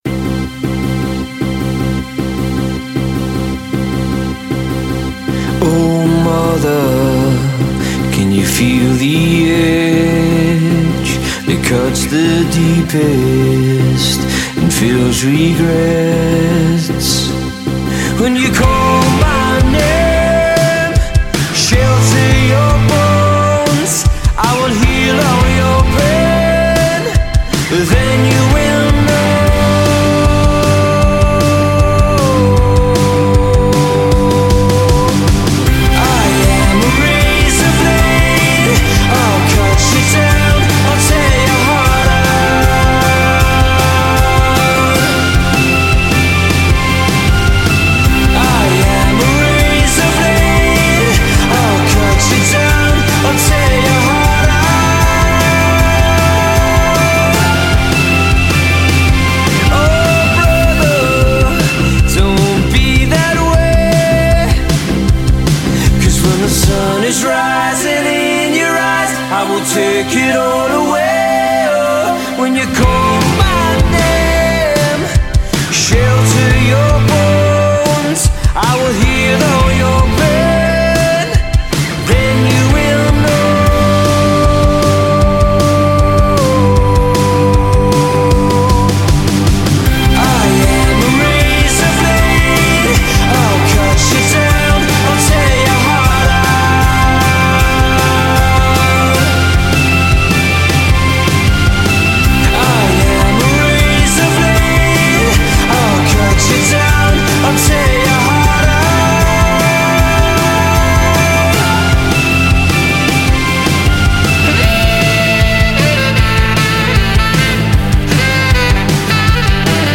Scottish five-piece
thumping piano chords, soaring synths